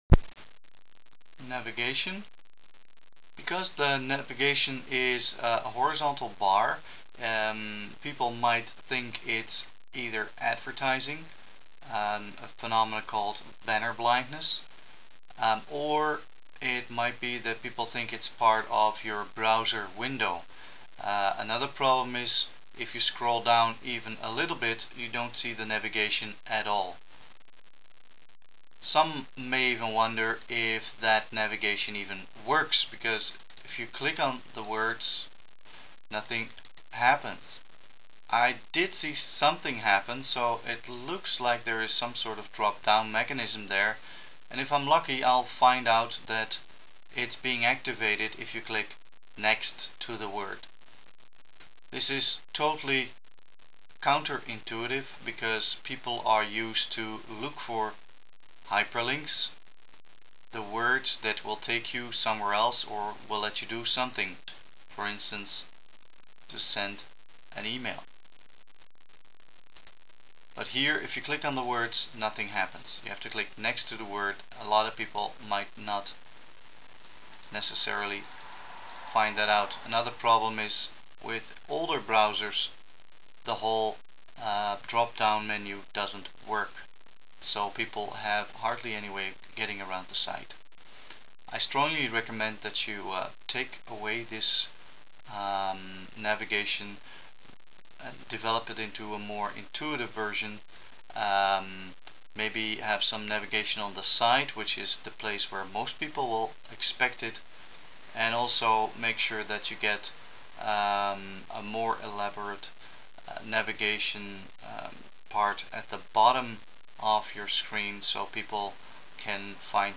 • you will receive a "movie file" with our commentary and mouse movements;